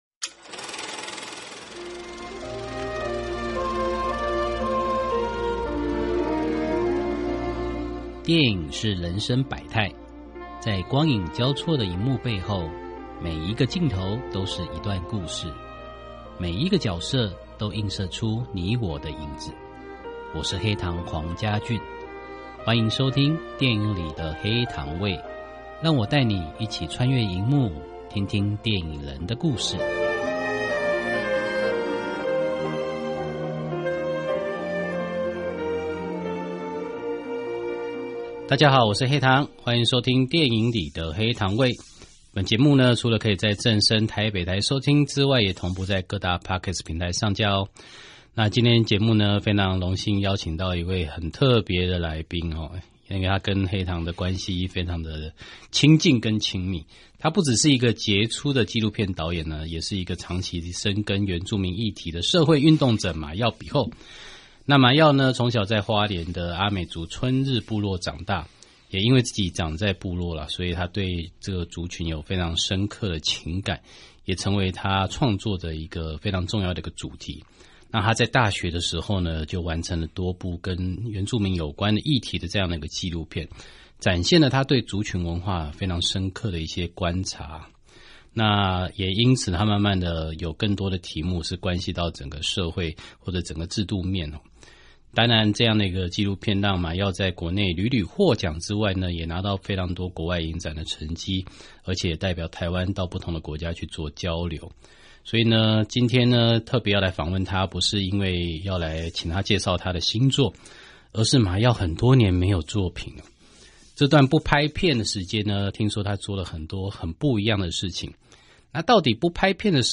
訪問大綱： 1.